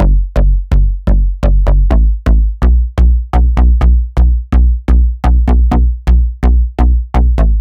VDE 126BPM Klondike Bass Root B.wav